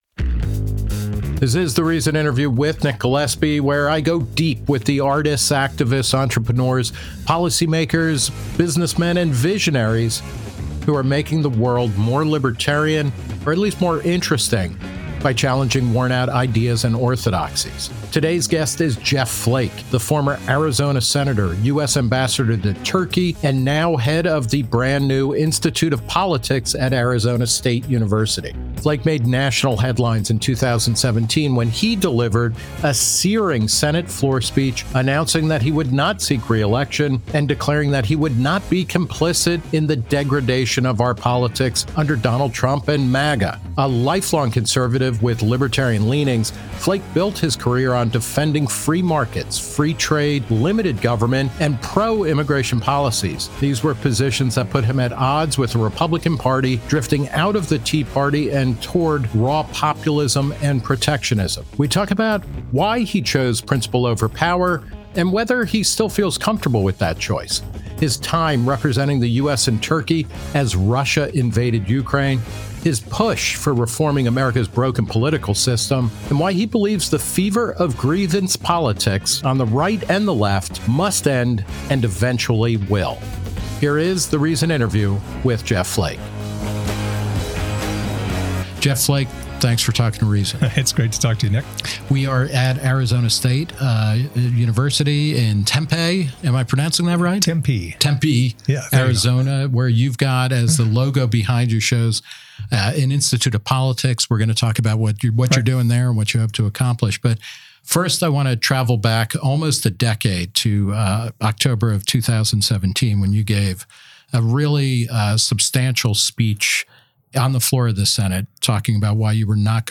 The Reason Interview With Nick Gillespie